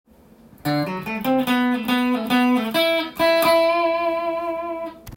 tab譜のkeyはAにしてみました。
Aメジャーペンタトニックスケール